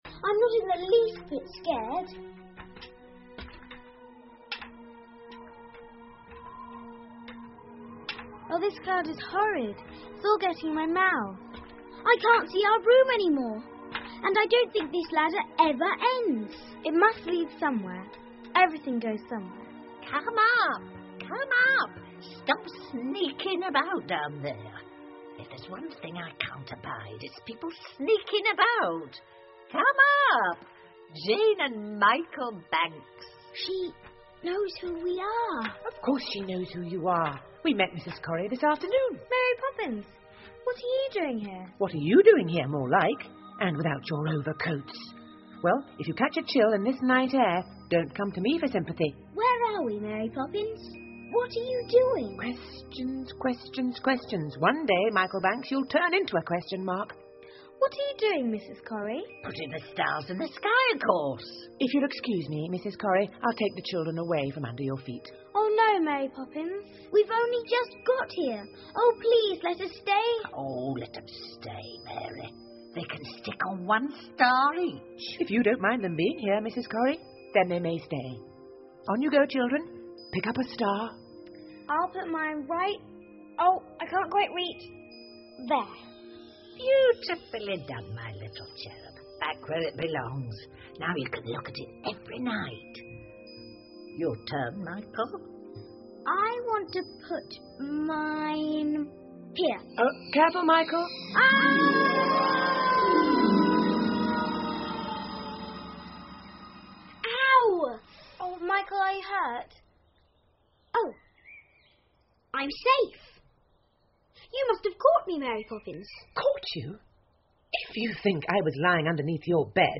玛丽阿姨归来了 Mary Poppins 儿童英文广播剧 6 听力文件下载—在线英语听力室